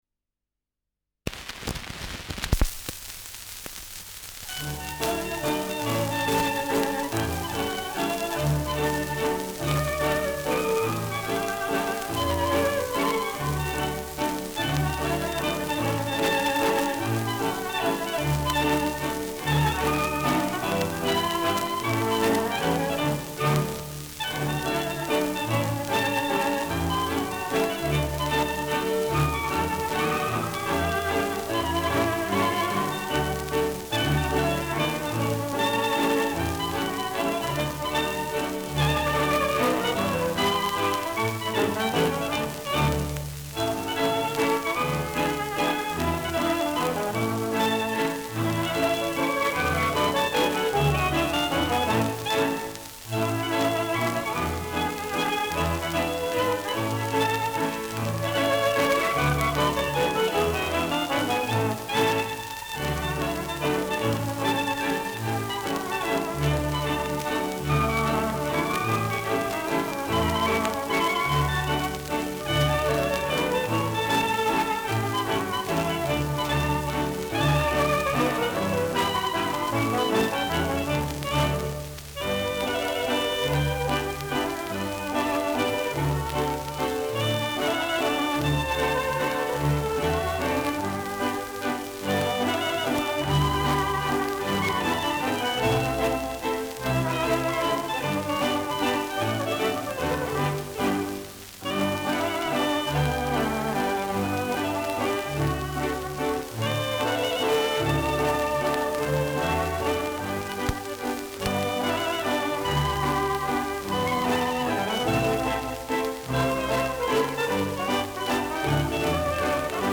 Schellackplatte
Stärkeres Grundrauschen : Gelegentlich leichtes Knacken
Ländlerkapelle* FVS-00018